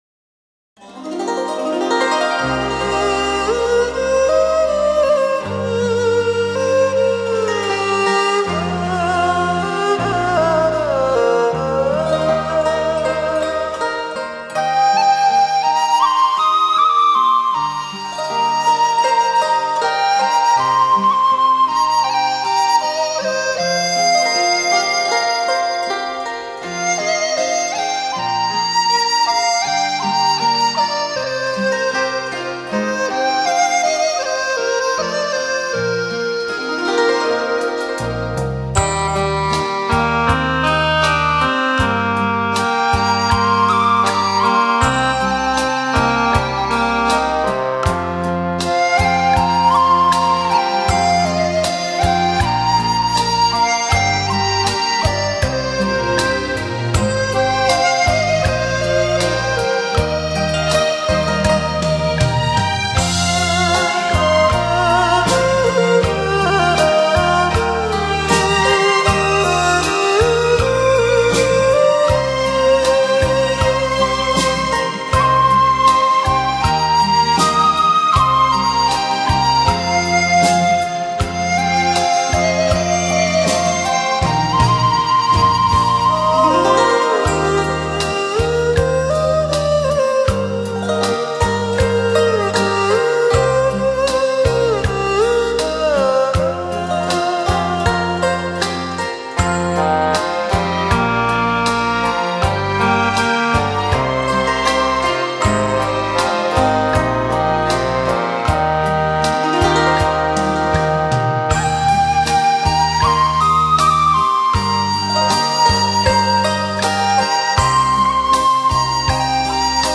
古筝+二胡+笛子+扬琴+小提琴+大提琴 广东粤曲清新柔丽，是中国曲艺家族中的重要成员。